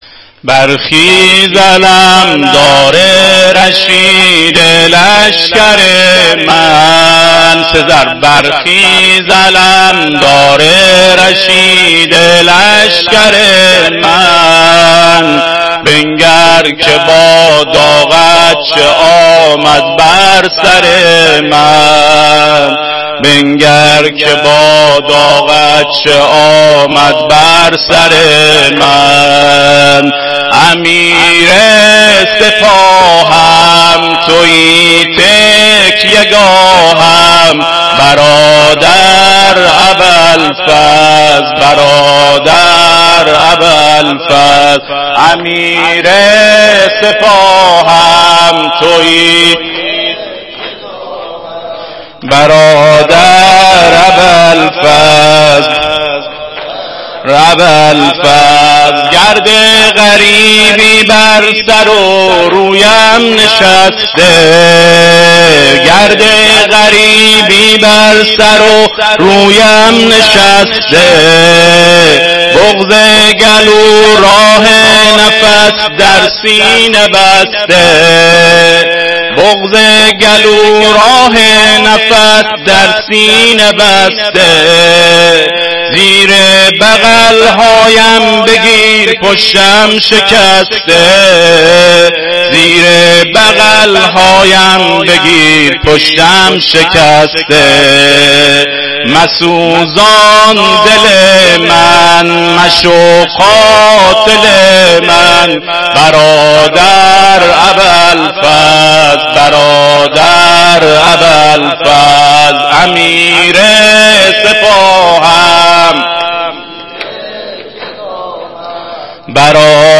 هیئت مهدیه احمد آباد